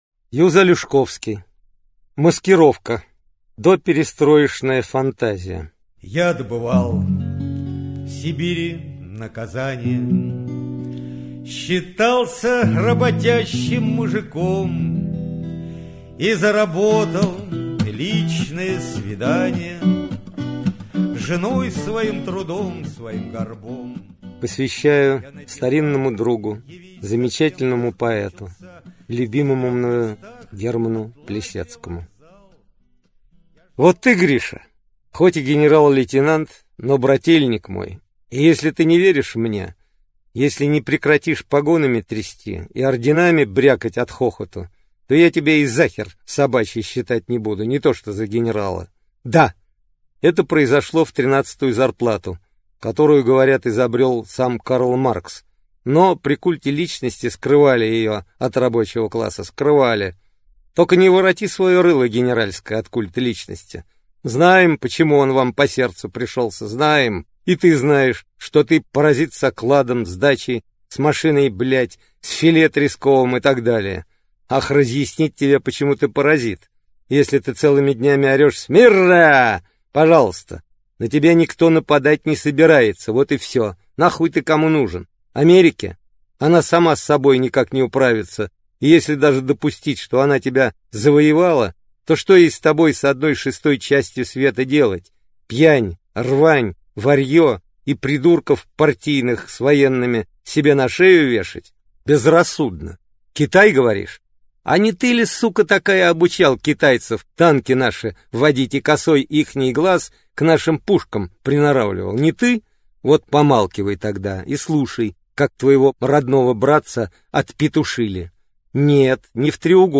Аудиокнига Маскировка | Библиотека аудиокниг
Aудиокнига Маскировка Автор Юз Алешковский Читает аудиокнигу Юз Алешковский.